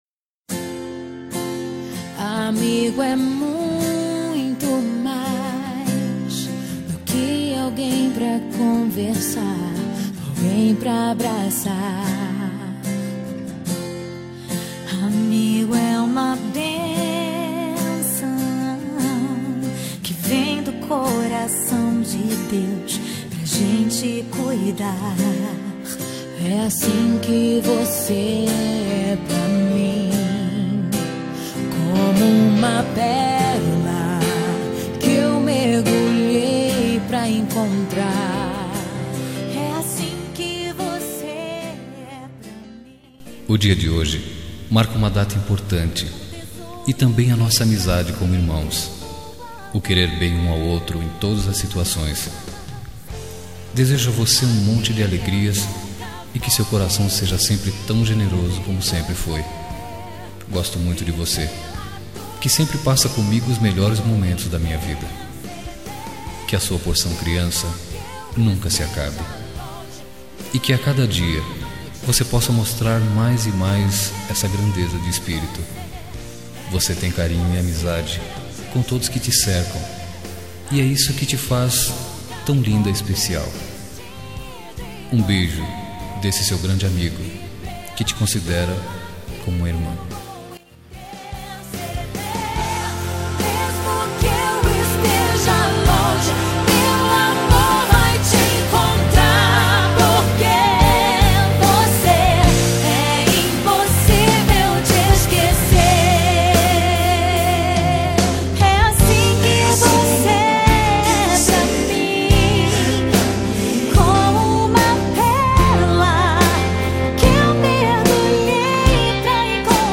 Aniversário de Amiga Gospel – Voz Masculina – Cód: 6011